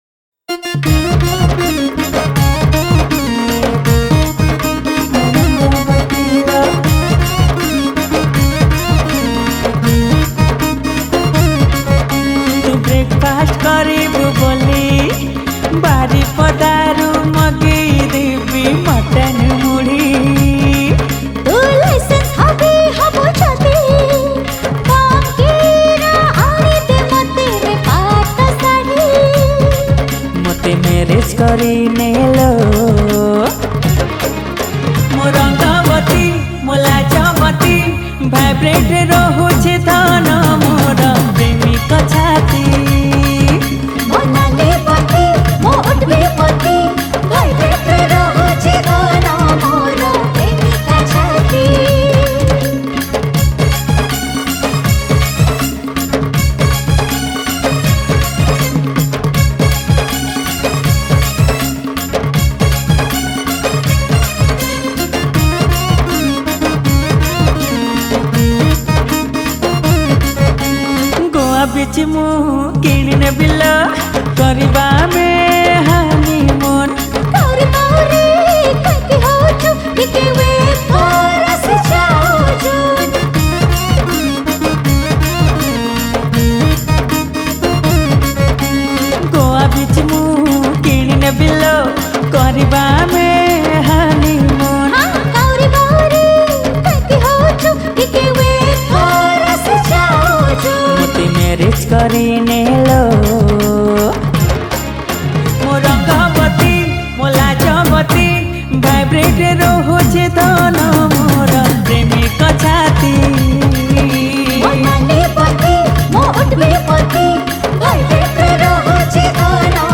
Song Type :Dance